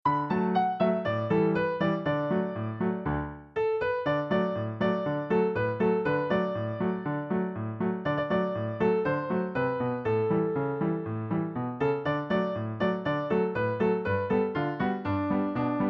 Sheet Music — Piano Solo Download
Downloadable Instrumental Track